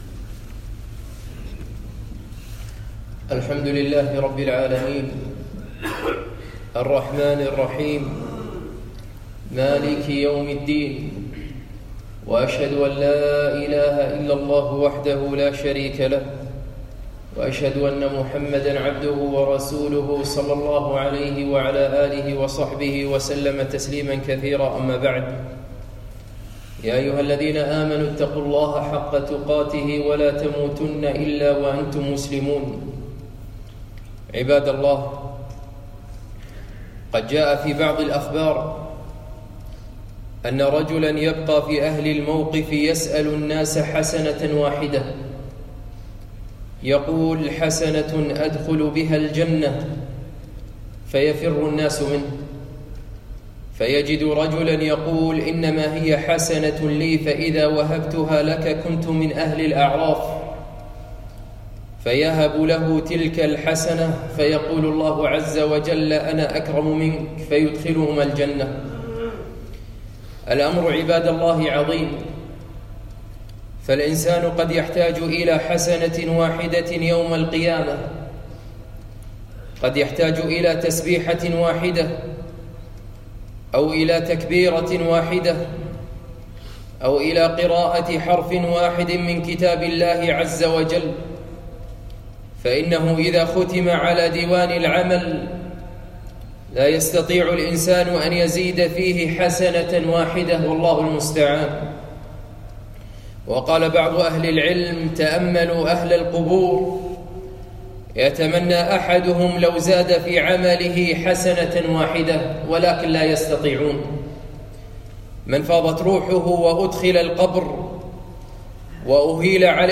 الجمعة 16 رمضان 1439 مسجد العلاء بن عقبة الفردوس الكويت